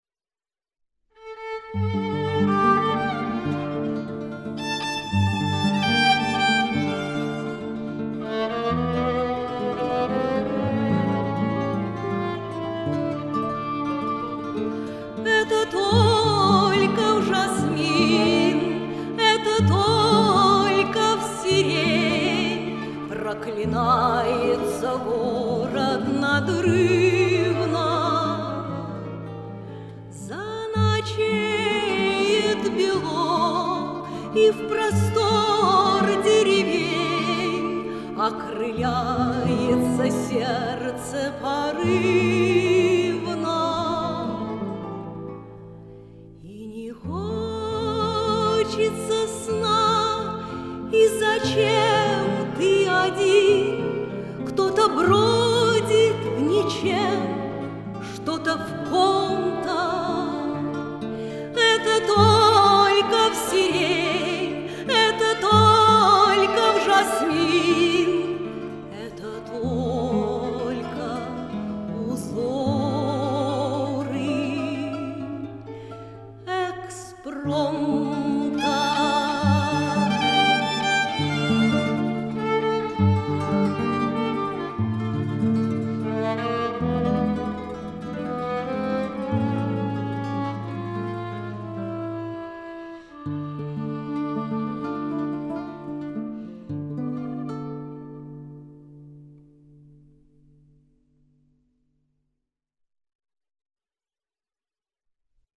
цыг. романс на ст.